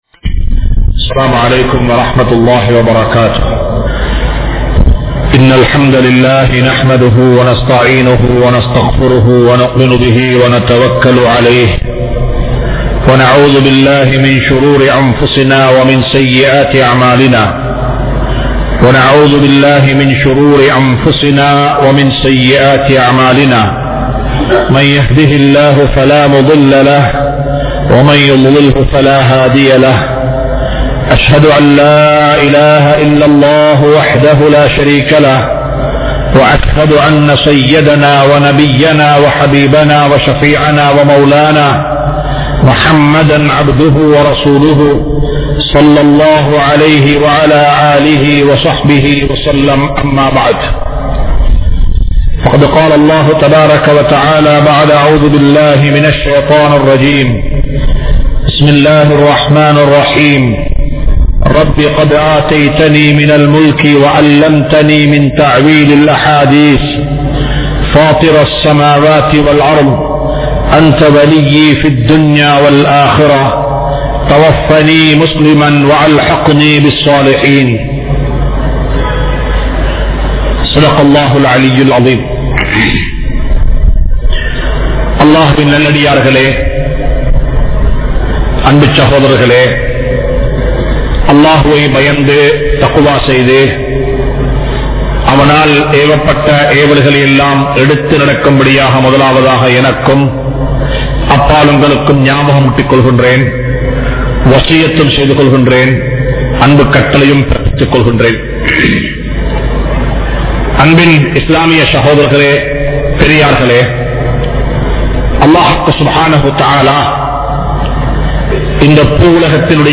Aatsiyaalarhalai Yaar Mudivu Seivathu?? (ஆட்சியாளர்களை யார் முடிவு செய்வது?) | Audio Bayans | All Ceylon Muslim Youth Community | Addalaichenai
Majma Ul Khairah Jumua Masjith (Nimal Road)